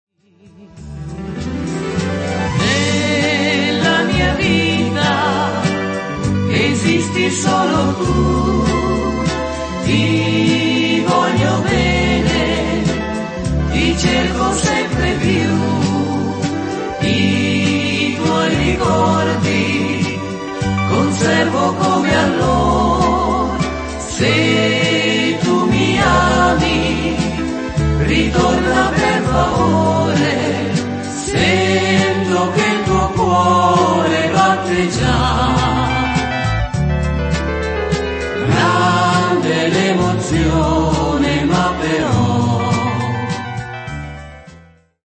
valzer lento